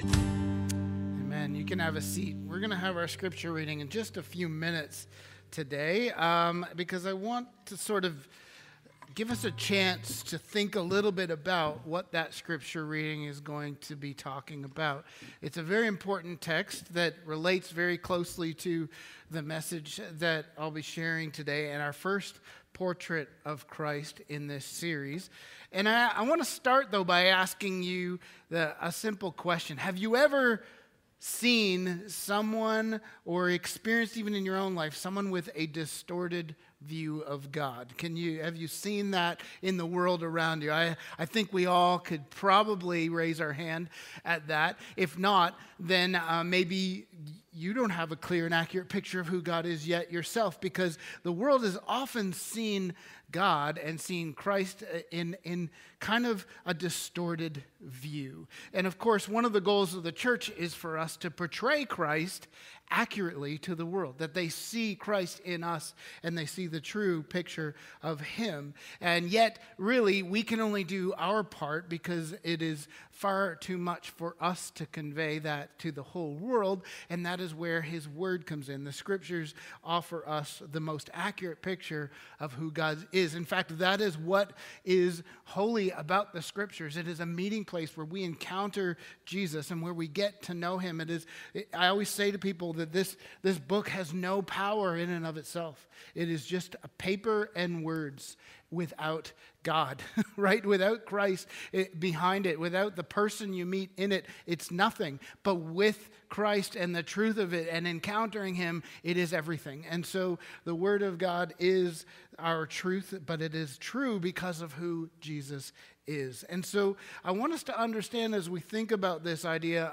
Sermons | Sunnyside Wesleyan Church
Easter Sunday